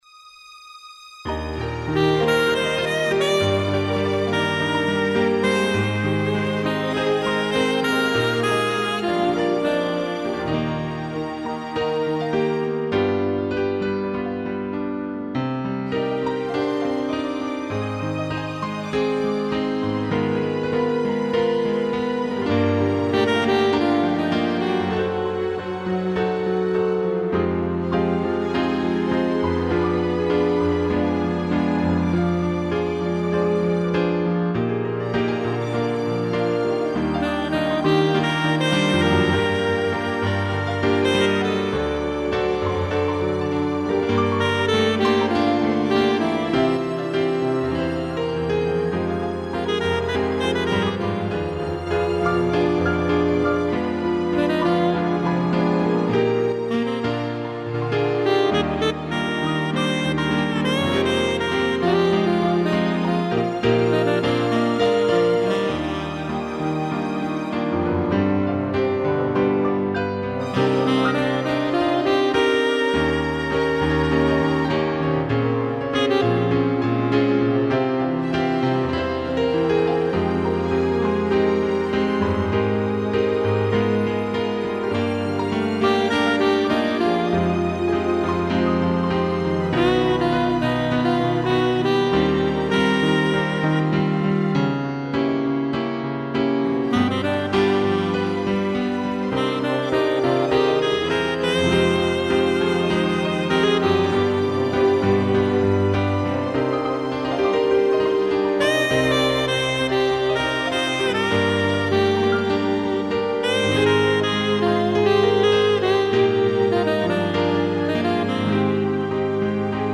2 pianos, cello, violino e sax
instrumental